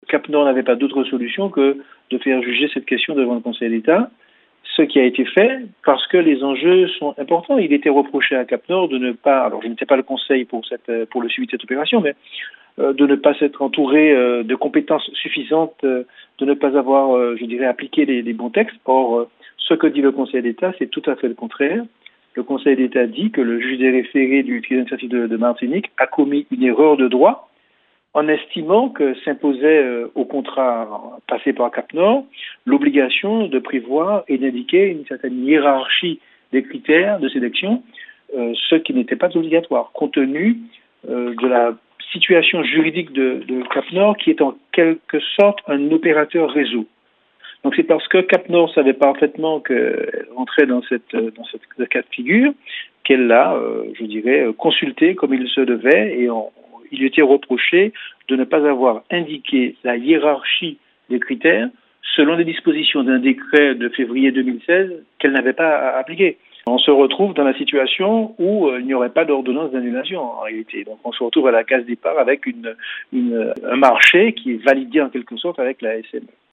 à écouter de nouveau sur Fusion à 17 heures 45